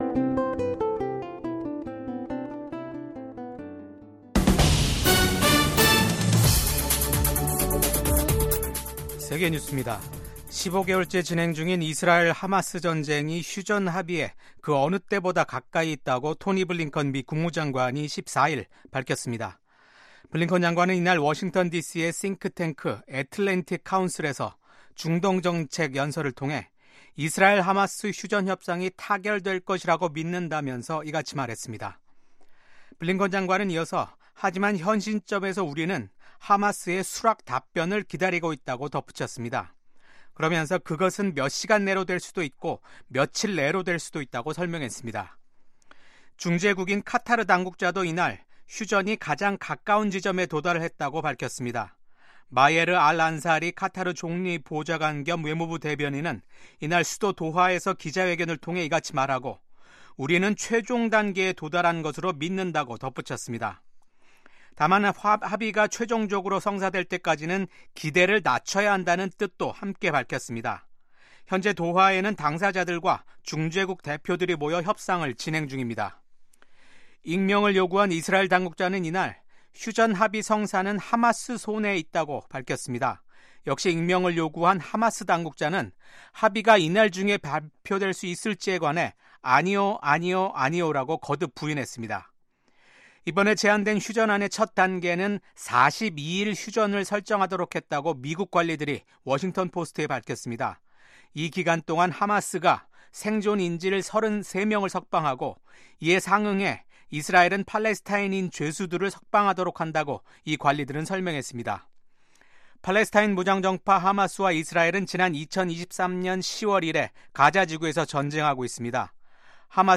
VOA 한국어 아침 뉴스 프로그램 '워싱턴 뉴스 광장'입니다. 북한이 8일만에 또 다시 탄도 미사일을 동해상으로 발사했습니다. 미국 국방부는 러시아 파병 북한군이 비교적 잘 훈련된 유능한 보병 병력으로, 우크라이나군에 위협이 되고 있다고 밝혔습니다. 미국의 한 전문가는 새로 들어설 미국의 도널드 트럼프 행정부가 혼란 상태에 빠진 한국 정부와 협력하는 것은 어려울 것으로 전망했습니다.